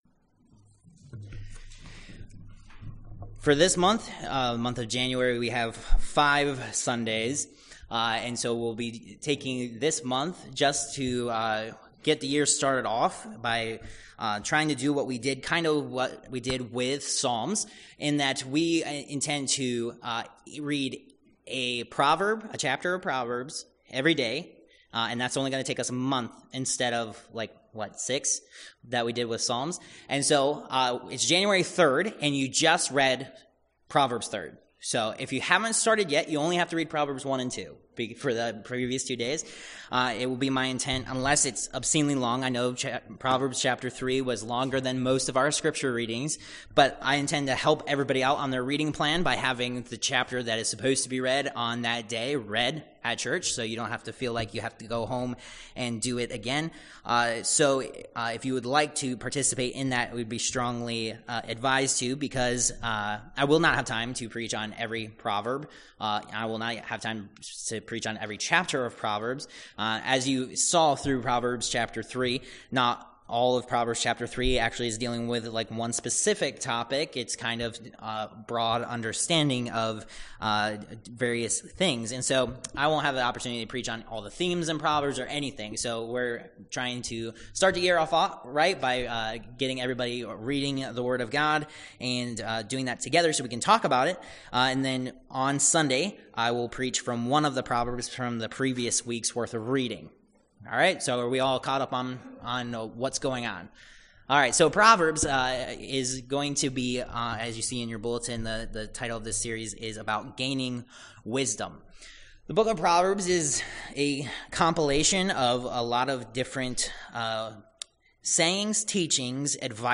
Proverbs 1-3 Service Type: Worship Service Topics